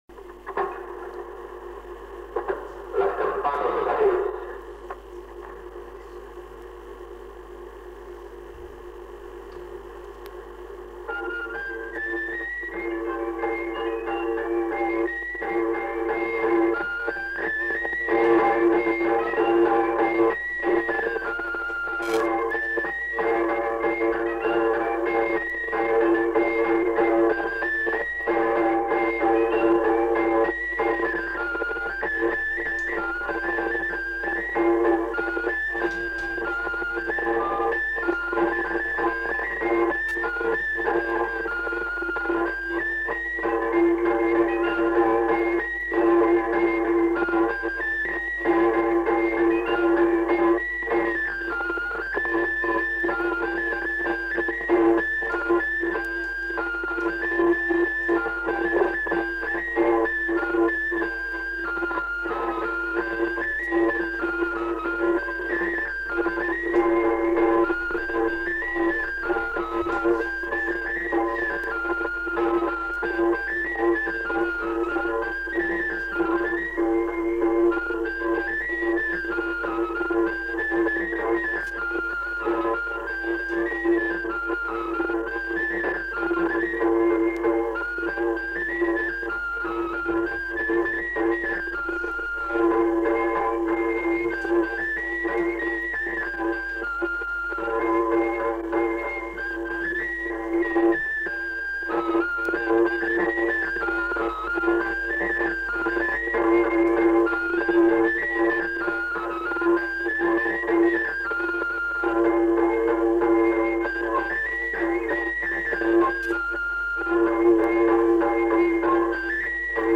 Aire culturelle : Béarn
Lieu : Bielle
Genre : morceau instrumental
Instrument de musique : flûte à trois trous ; tambourin à cordes
Danse : branlo airejan